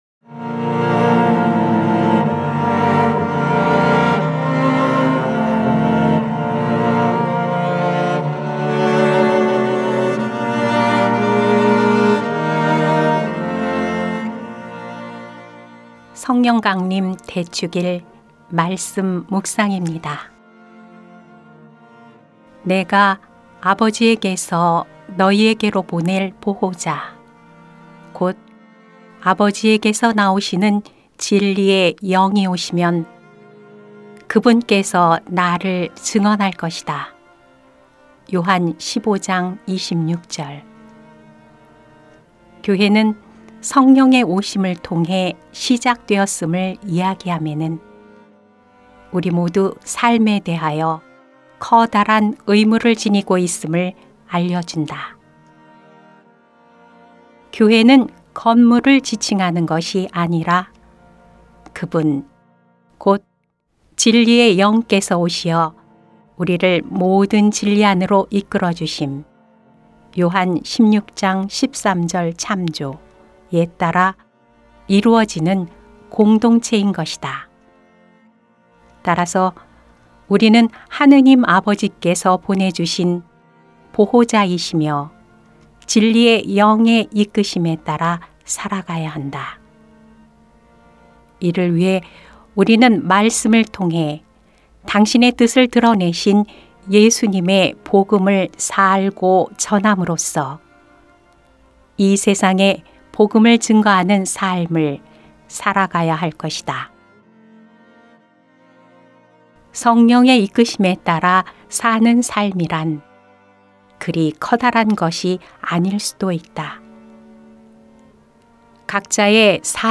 2021년 5월 23일 성령강림대축일 - 말씀묵상 듣기(☜파란색 글씨를 클릭하세요)